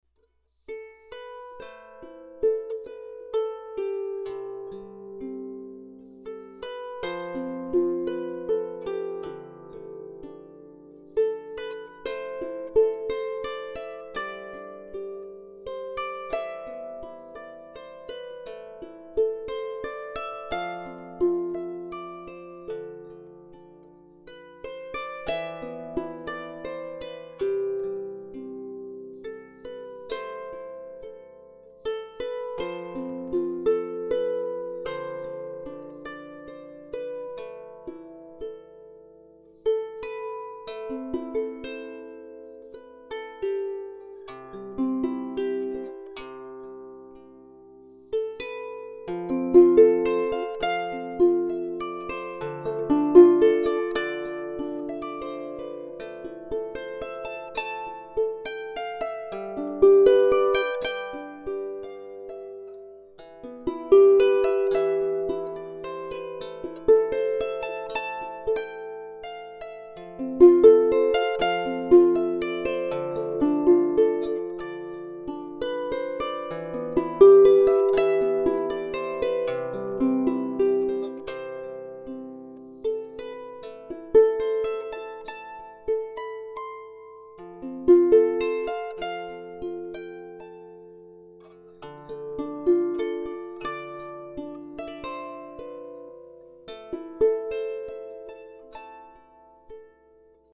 Harp Tunes
This is the first tune I wrote, back in early 1999, 3 months after starting to play harp.